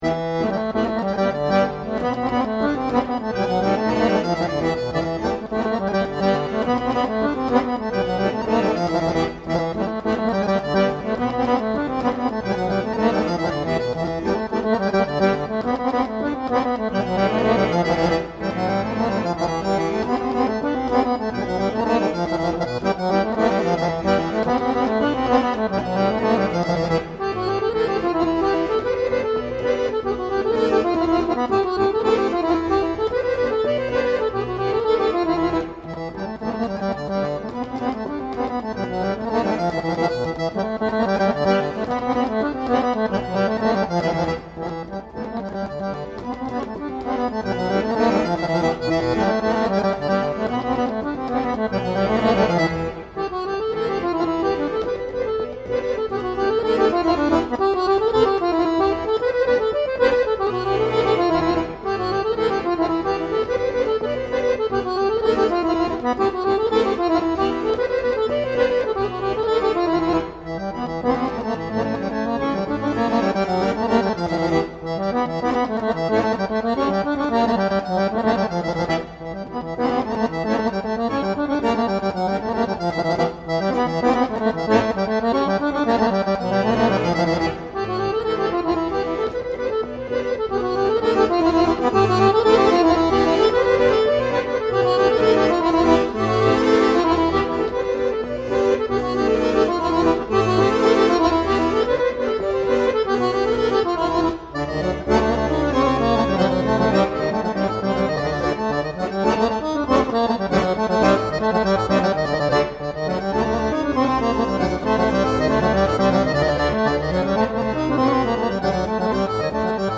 Bulgarian Instrumental